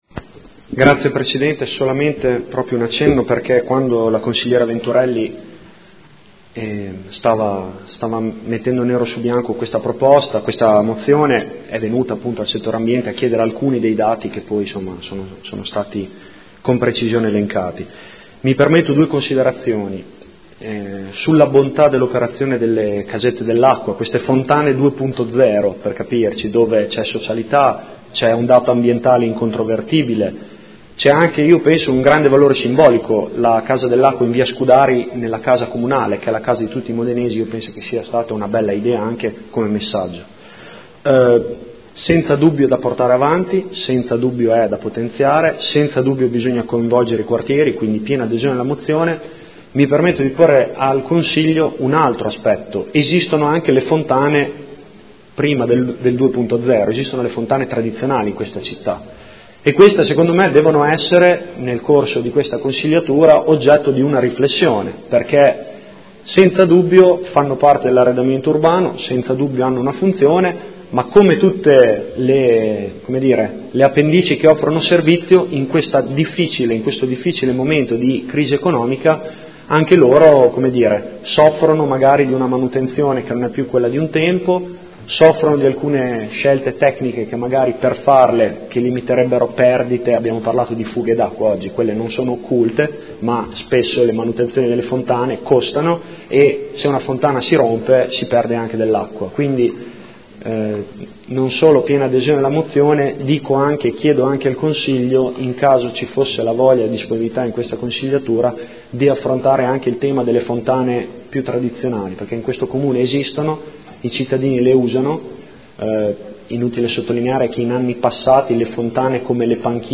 Giulio Guerzoni — Sito Audio Consiglio Comunale